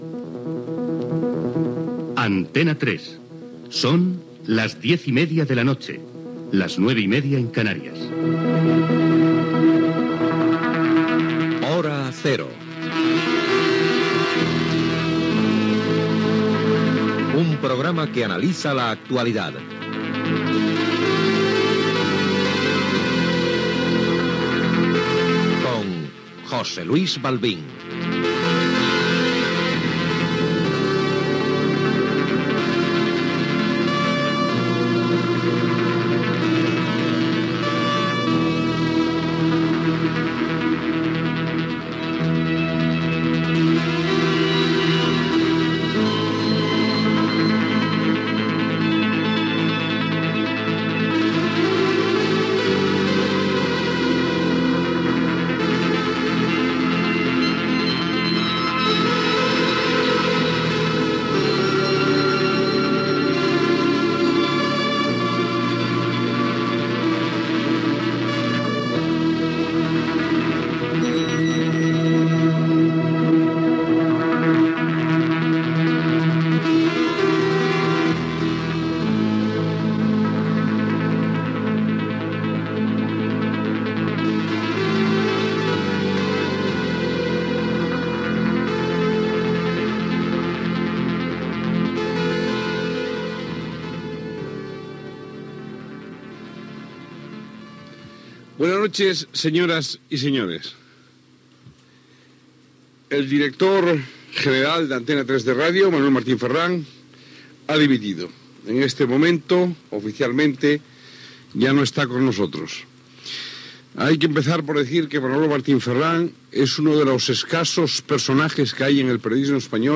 Indicatiu de l'emissora, careta, sintonia, el director general d'Antena 3, Manuel Martín Ferrand, ha dimitit com a director general d'Antena 3 Televisión (en les hores posteriors de la compra d'Antena 3 Radio pel grup Prisa que posteriorment va desmantellar per utilitzar les seves freqüències i crear Sinfo Radio).
Informatiu